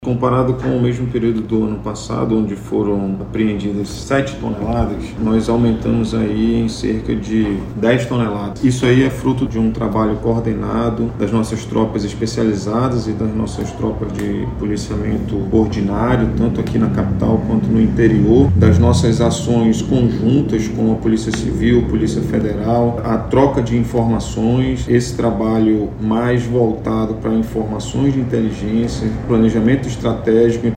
SONORA-1-CORONEL-KLINGER-PAIVA.mp3